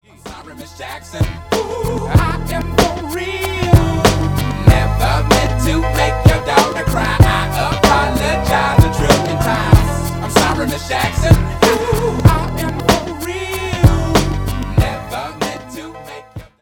Угадать хип-хоп дуэт.